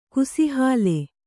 ♪ kusihāle